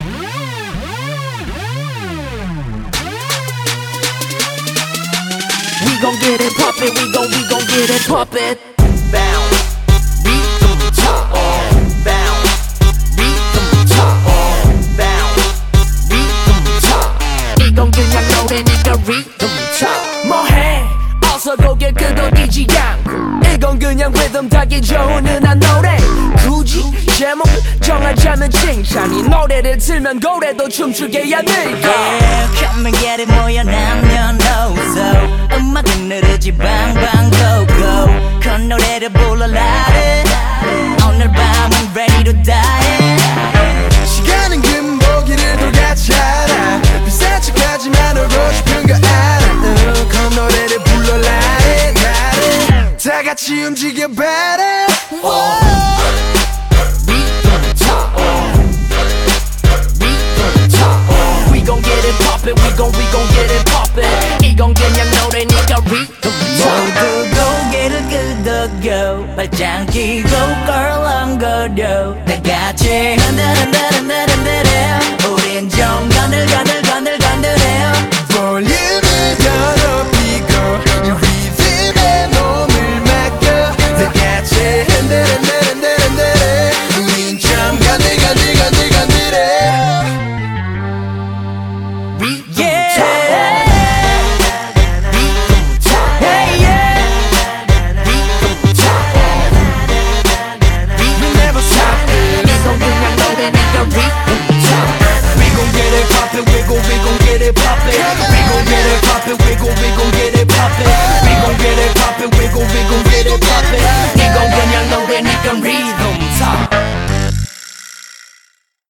BPM20-82
MP3 QualityMusic Cut